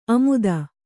♪ amuda